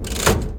lever3.wav